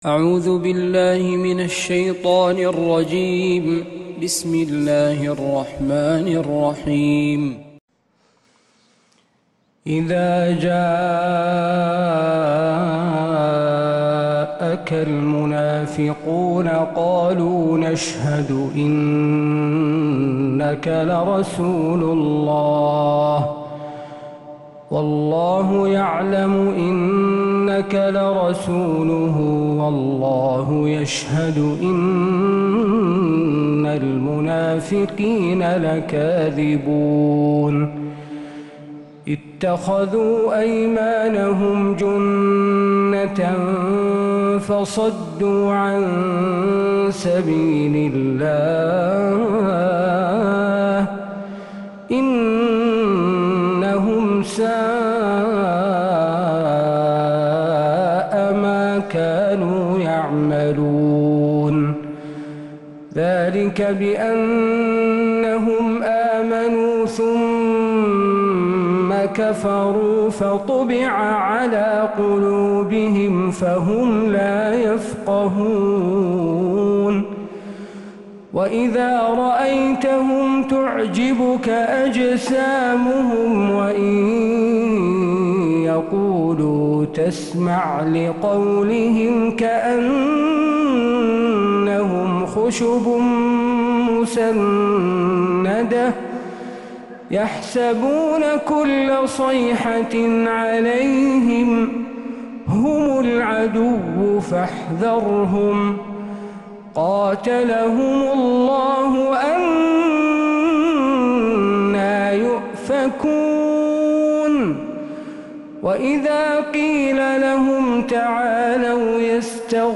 سورة المنافقون كاملة من فجريات الحرم النبوي